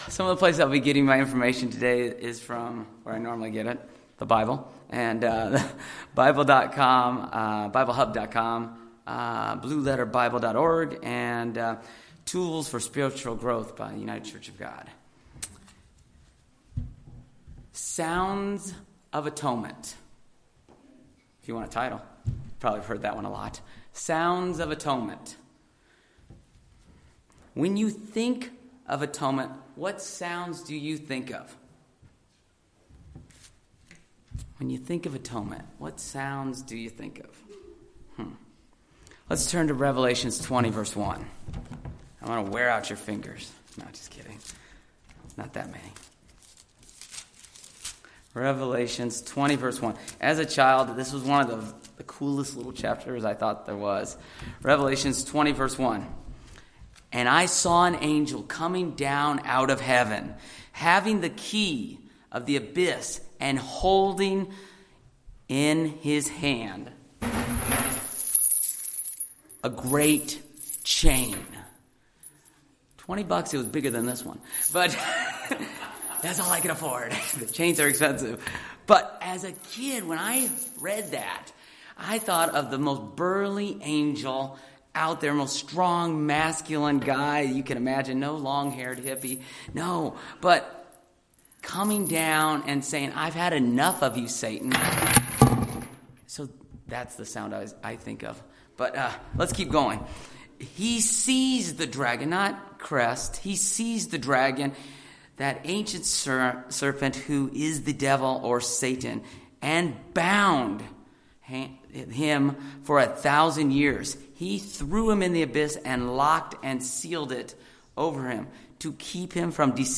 This day is a day of turning to God, becoming close to Him. This sermon takes a look at how we can refocus our prayer life during these Holy Days.